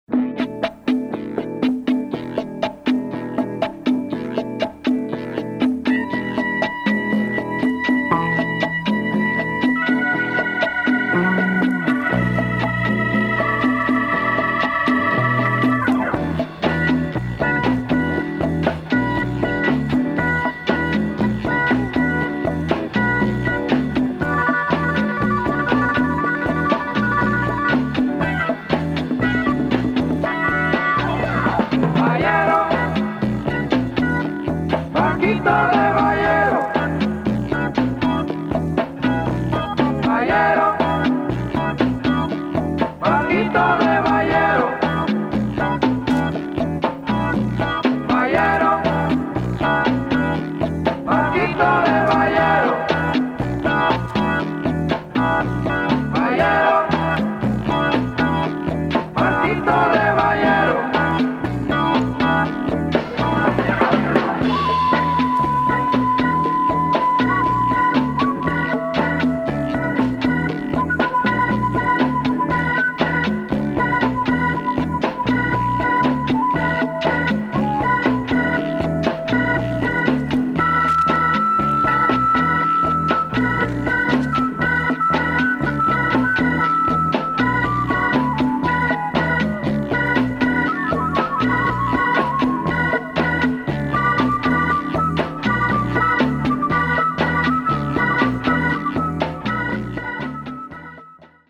Dope groovy latin music
with a psych organ storm.